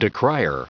Prononciation du mot decrier en anglais (fichier audio)
Prononciation du mot : decrier